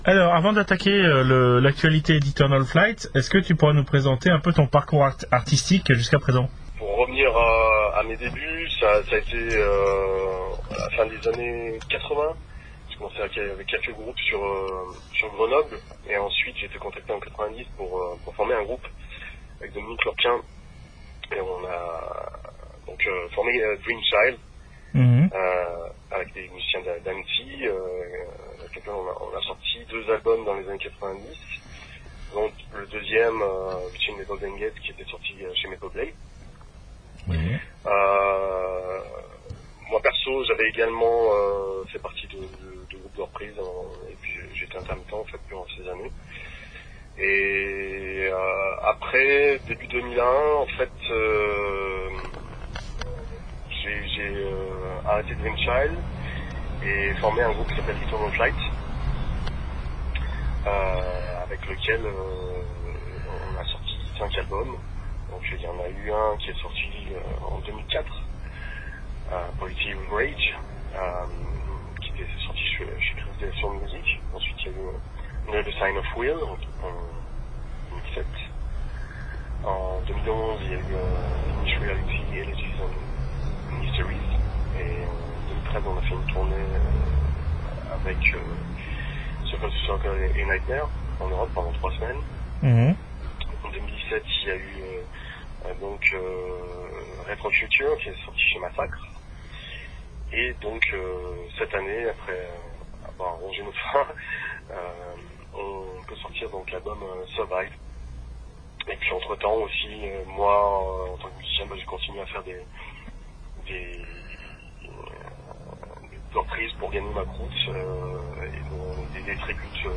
ETERNAL FLIGHT (Interview